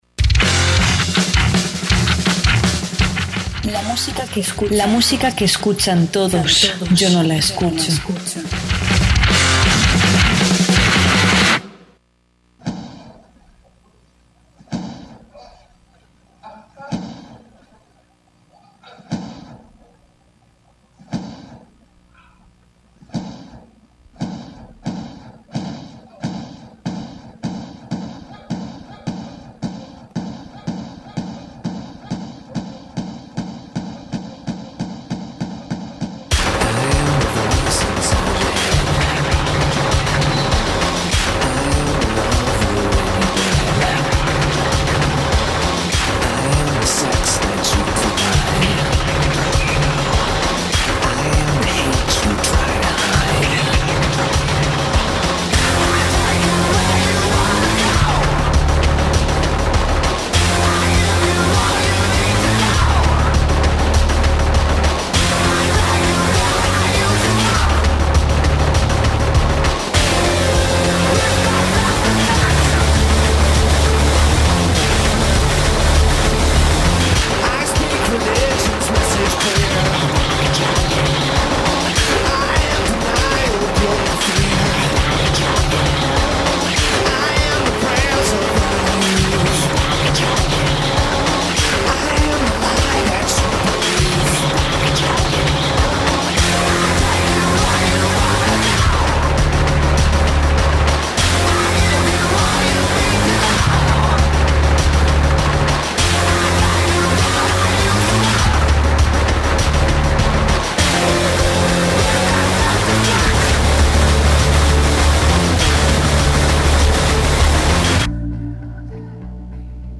punk pop
heavy metal